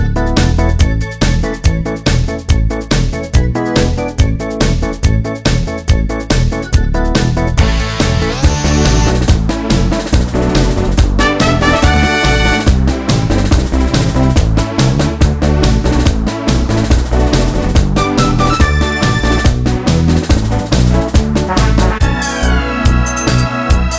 no Backing Vocals Comedy/Novelty 3:36 Buy £1.50